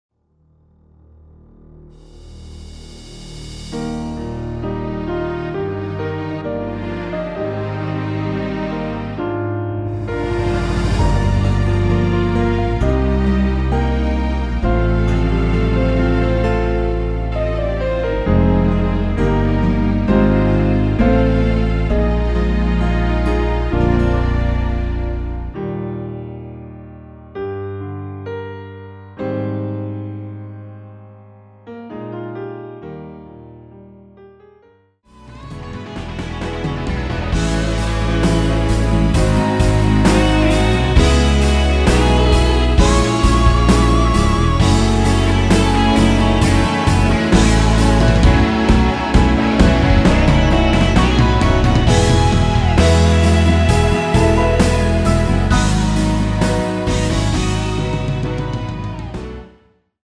Eb
◈ 곡명 옆 (-1)은 반음 내림, (+1)은 반음 올림 입니다.
앞부분30초, 뒷부분30초씩 편집해서 올려 드리고 있습니다.